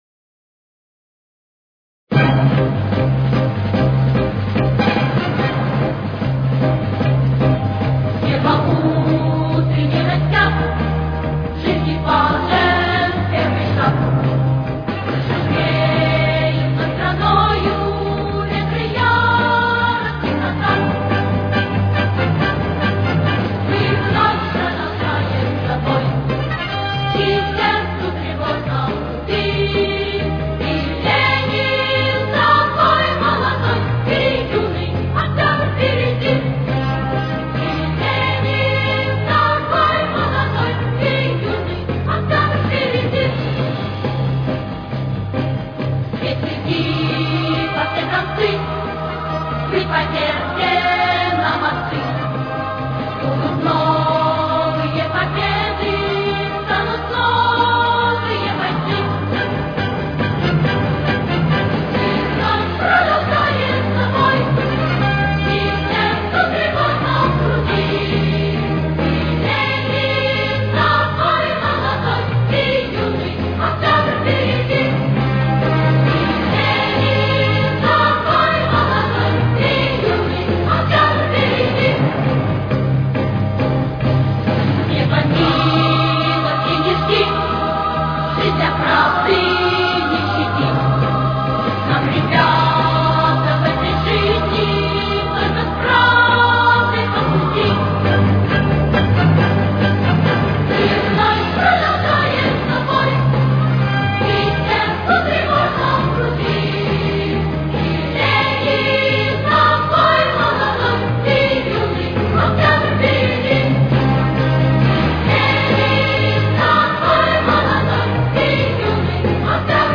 До минор. Темп: 142.